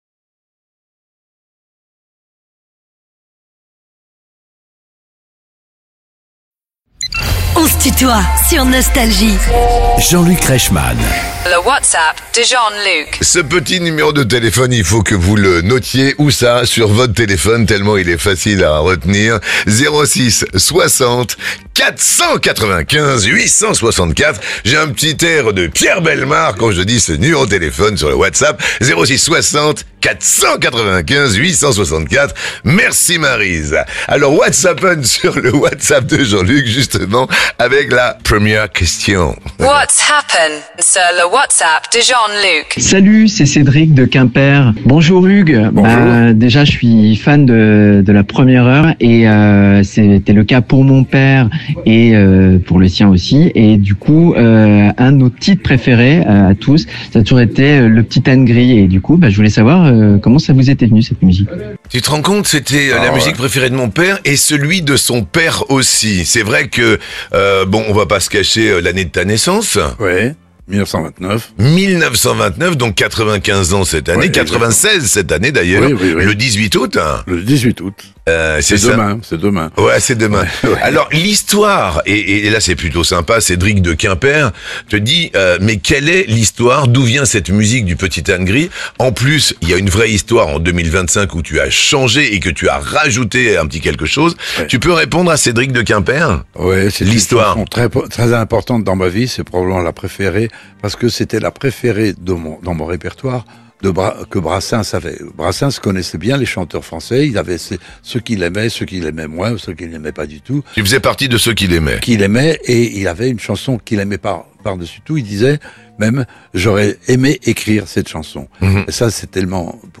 Les plus grands artistes sont en interview sur Nostalgie.